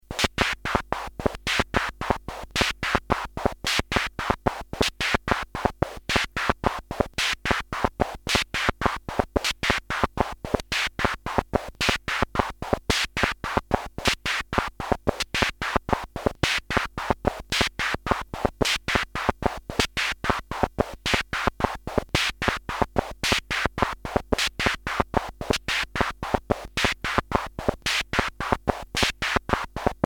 This article gives seven examples of sounds you can produce with Sound Lab, the simple single-board analogue synthesiser that I described in the earlier article Sound Lab - a Simple Analogue Synthesiser:
Machinery
machinery.mp3